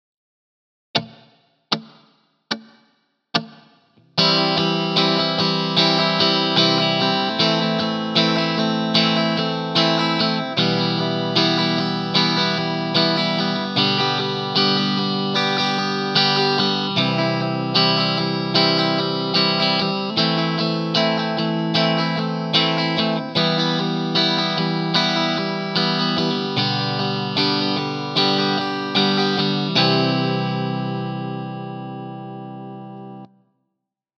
Rythme : 2 doubles croches - croche
Audio : 4 X BHB sur chaque accord (DO LAm MIm SOL)